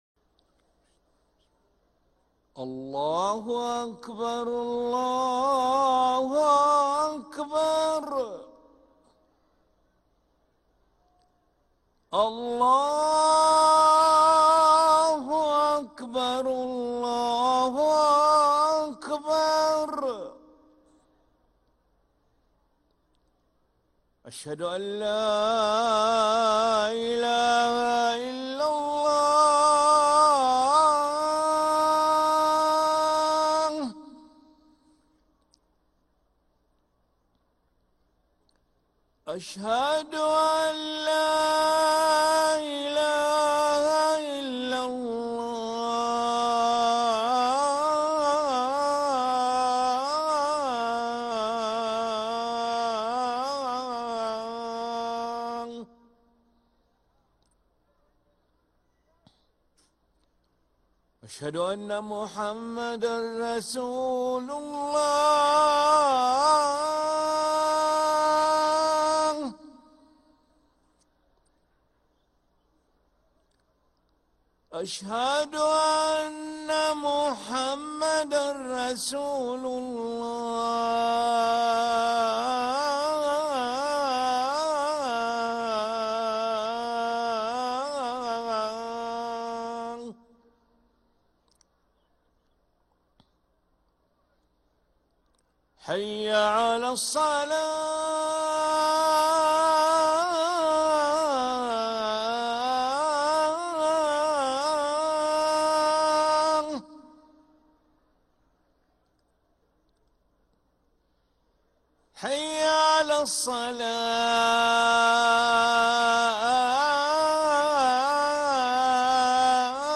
أذان العشاء للمؤذن علي ملا الاحد 3 ربيع الثاني 1446هـ > ١٤٤٦ 🕋 > ركن الأذان 🕋 > المزيد - تلاوات الحرمين